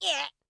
Npc Catpain Sound Effect
npc-catpain.mp3